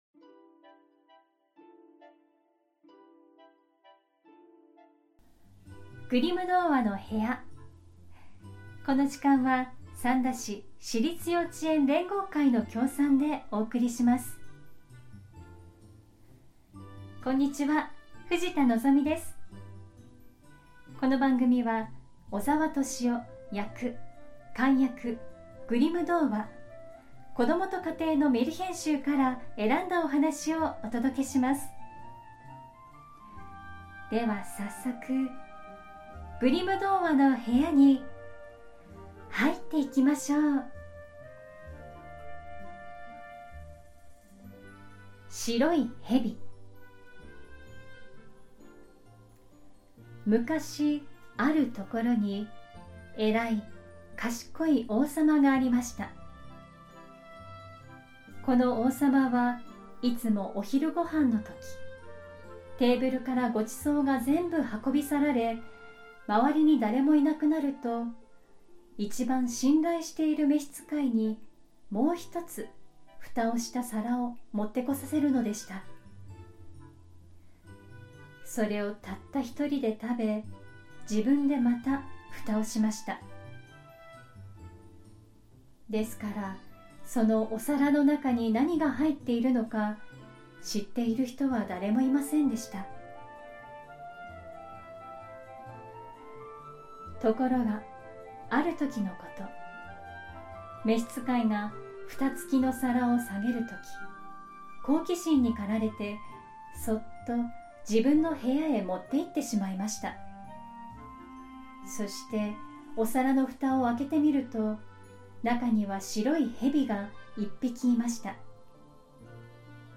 グリム兄弟によって集められたメルヒェン（昔話）を、翻訳そのままに読み聞かせします📖 今回お届けするのは『白いヘビ』。 2025年は巳年🐍 ということで、今回はこのおはなしをお届けします📖 白蛇は縁起の良いものとして知られていますが、異国の地でも同じような存在なのでしょうか✨ 昔話の本当のストーリーを、お子さんも大人の方もどうぞお楽しみください🌷